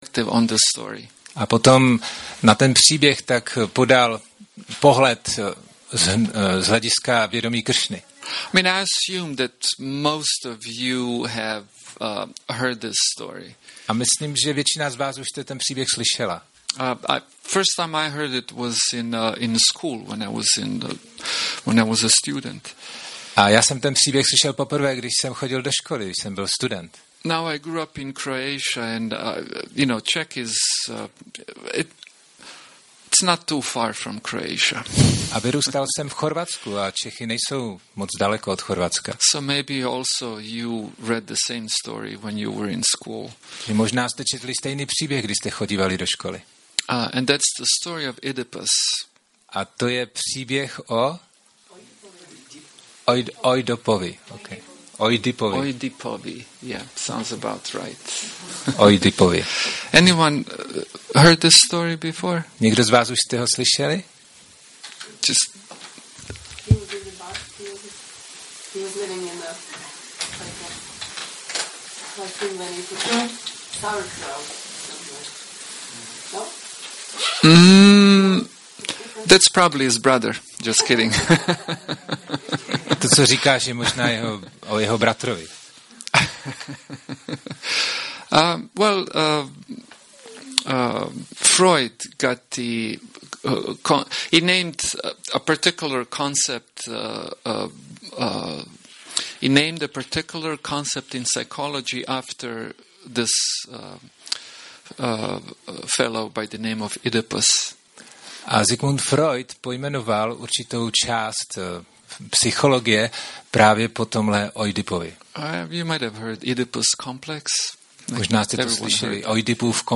Přednáška Nedělní program